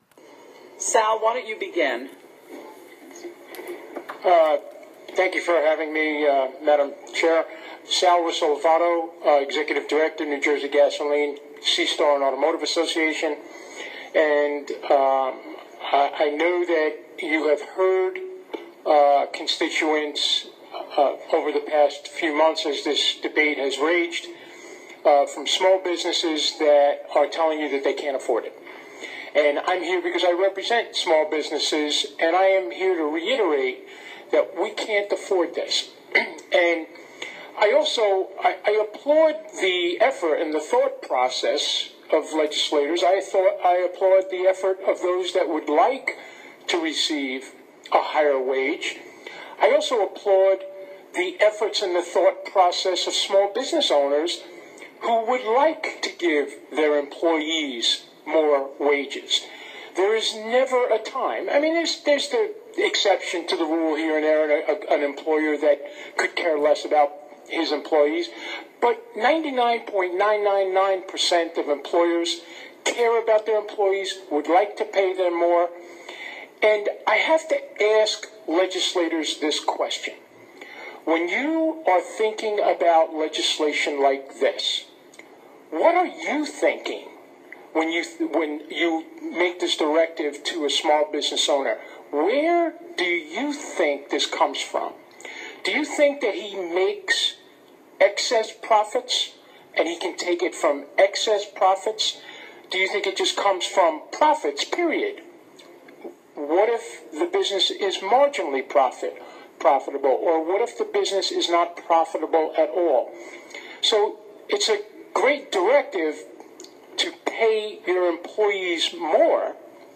HERE, and I have included a picture from the hearing at the end of this message.